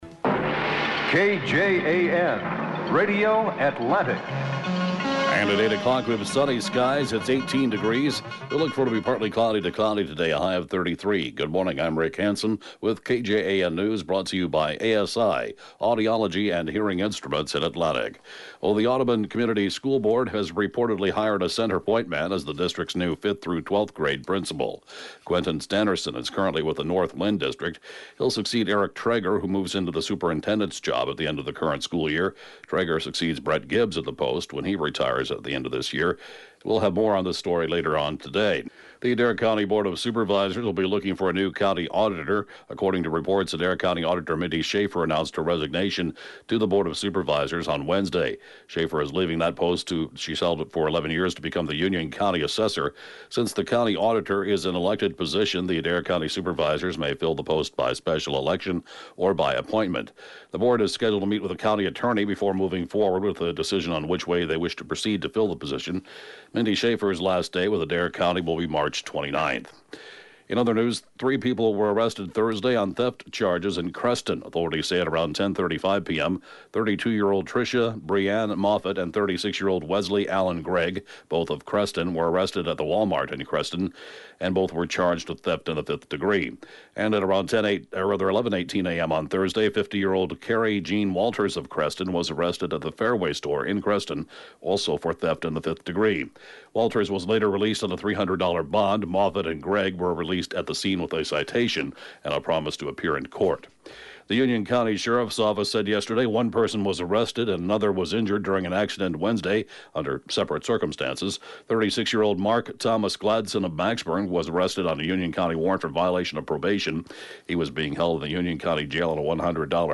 News, Podcasts